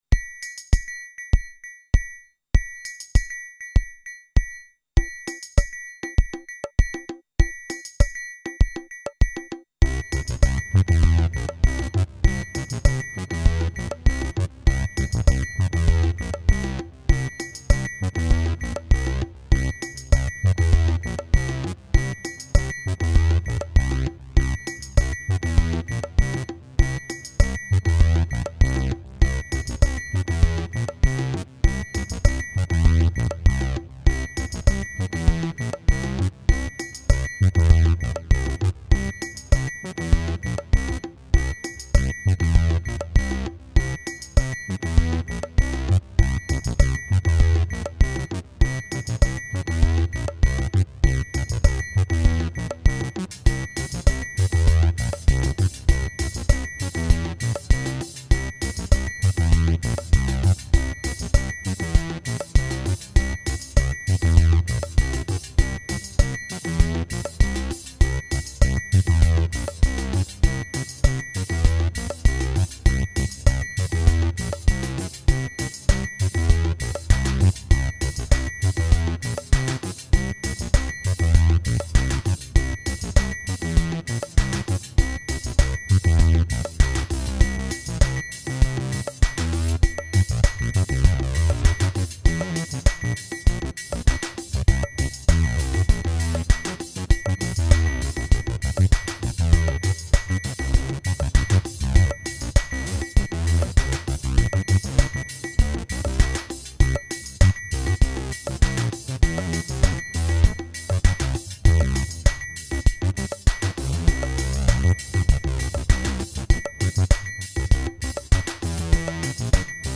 Tungt monotont melodifestivalbidrag 2013
Den ligger nog lite för lågt, lite som mellan en triangel och en ko-klocka. Som en agogo, typ.
Men det lät rätt fräckt, så igång med trummaskin och lite synthbas. Jag började jazza med filtret på synthen och på den vägen är det.
Så det blir inte fullt så monotont trots att det bara är en enda takt som loopas i 8 miunter.
Tung bas, kan vara intressant för medlemmar med rejäla bashögtalare. En del faseffekter i bassynthen kan kanske också låta intressant för den som har rum nog att klara det.